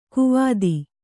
♪ kuvādi